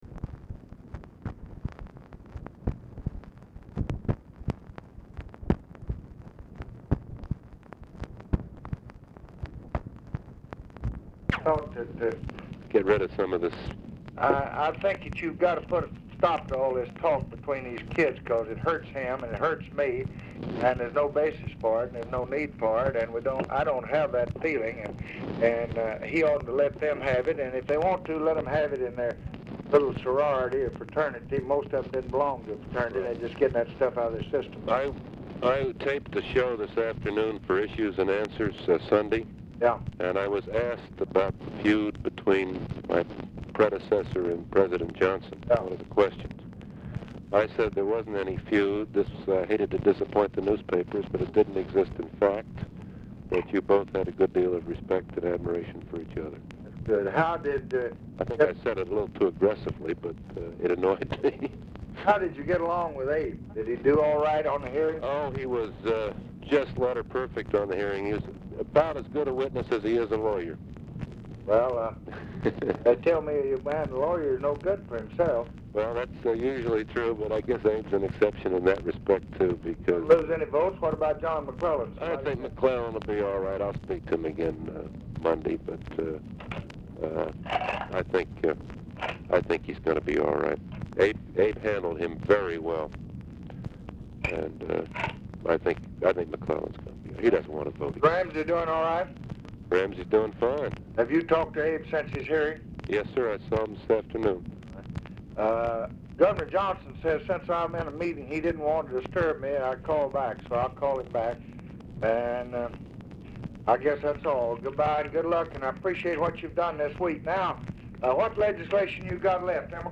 Telephone conversation # 8514, sound recording, LBJ and NICHOLAS KATZENBACH, 8/6/1965, 6:10PM | Discover LBJ
RECORDING STARTS AFTER CONVERSATION HAS BEGUN
Format Dictation belt
Specific Item Type Telephone conversation